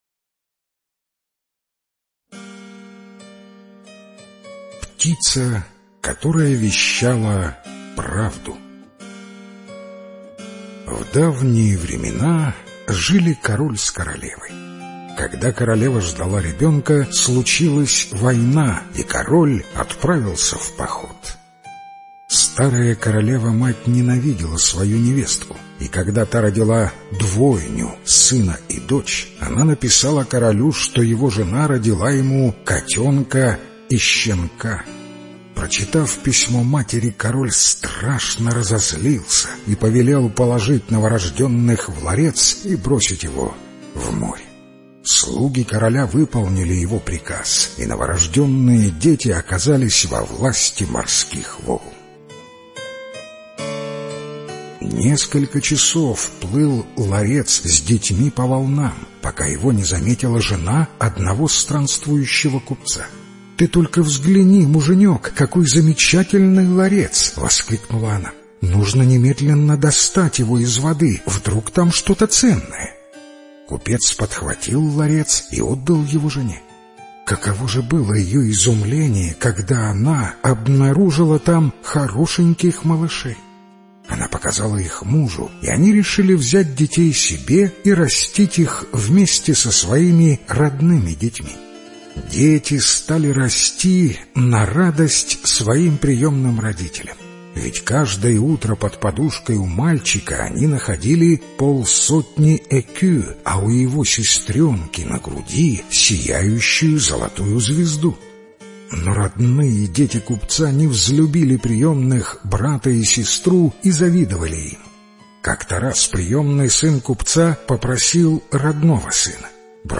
Правдивая птица - французская аудиосказка - слушать онлайн